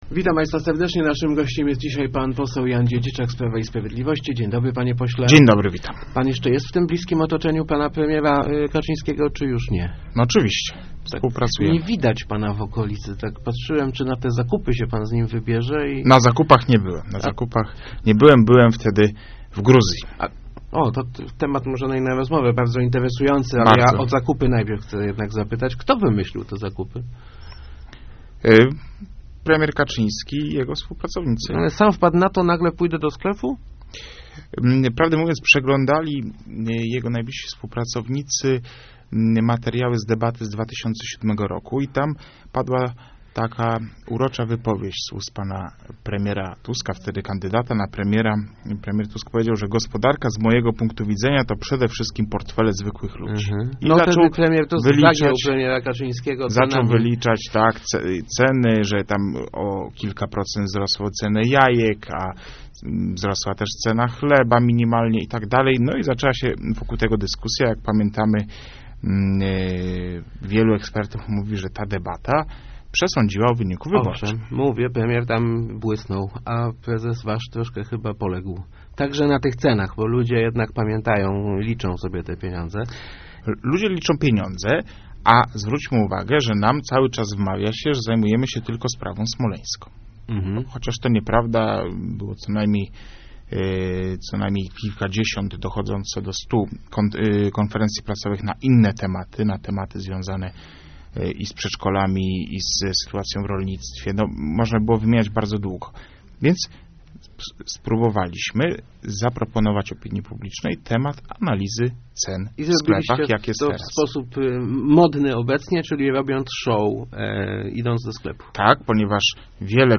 Przebiliśmy się do społeczeństwa z innym tematem niż Smoleńsk - mówił w Rozmowach Elki poseł PiS Jan Dziedziczak, komentując sprawę słynnych "zakupów" prezesa partii Jarosława Kaczyńskiego.